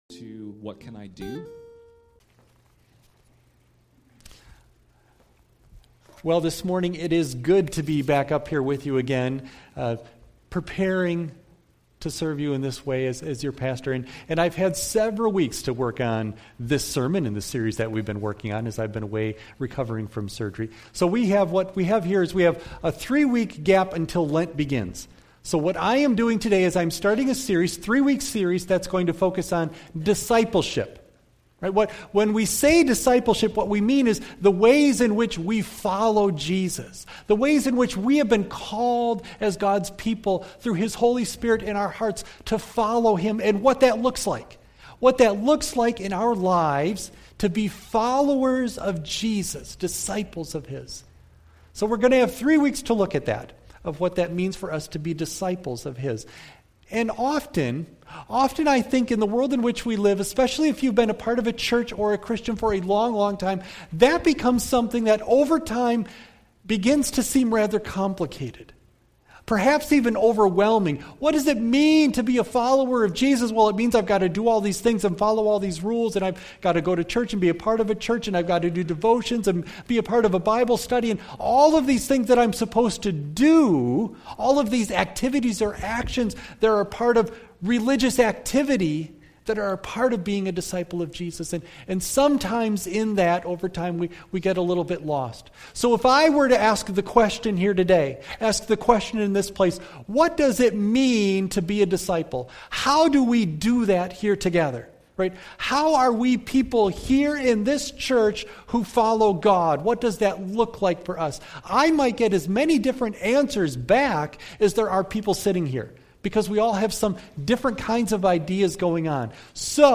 Psalm 19 Service Type: Sunday AM Bible Text